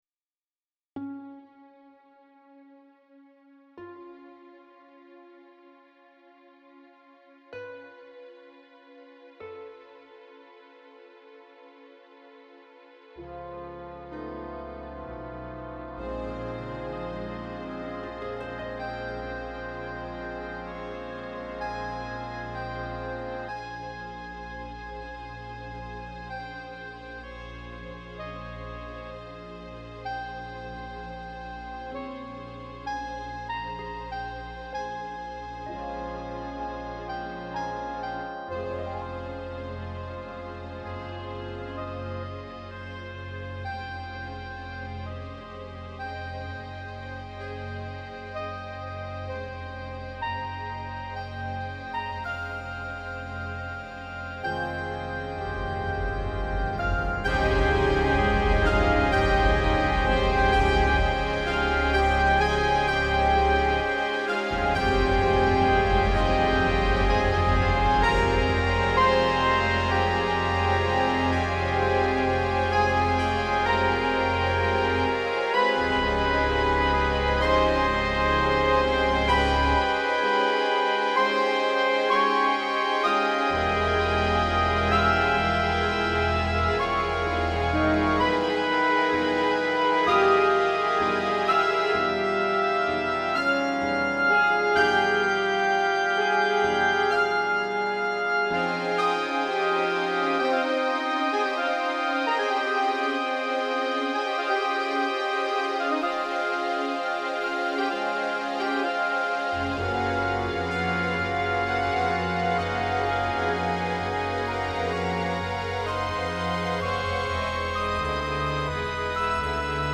Piece for Orchestra